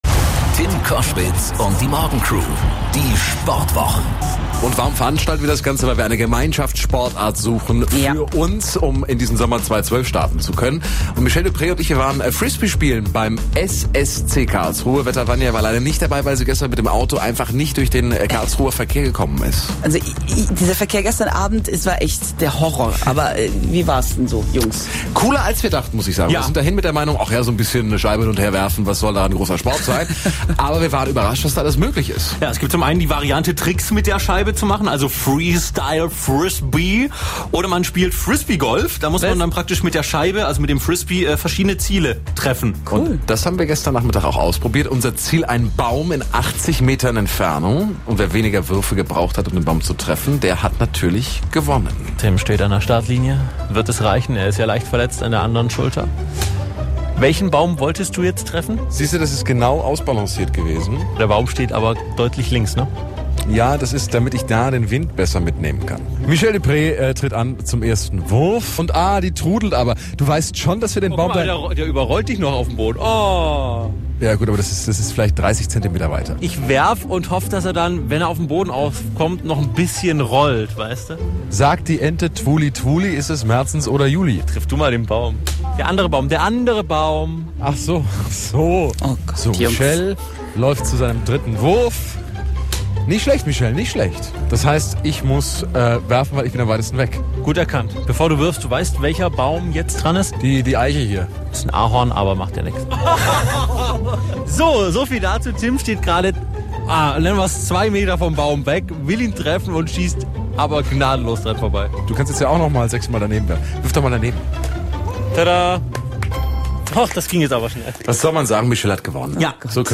Radiobeitrag: Die Neue Welle - Sportwoche
NeueWelleInterview